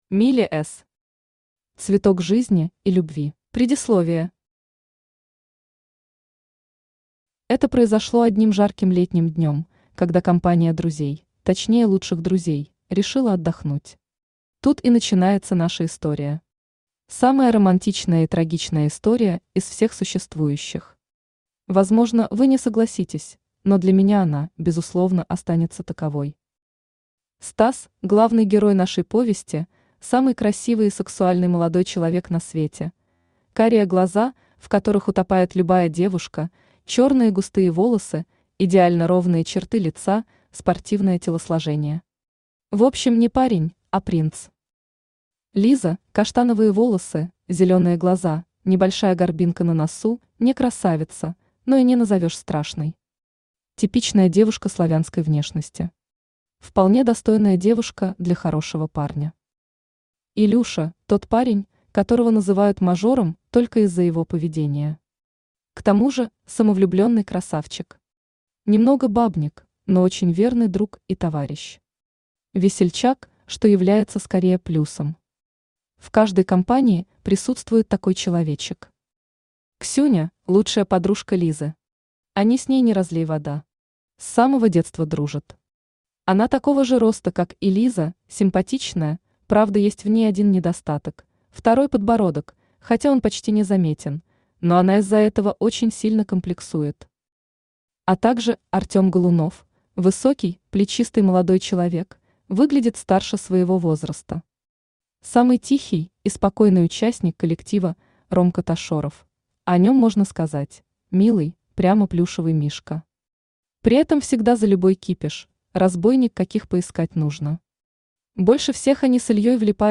Аудиокнига Цветок жизни и любви | Библиотека аудиокниг
Aудиокнига Цветок жизни и любви Автор Миля С с Читает аудиокнигу Авточтец ЛитРес.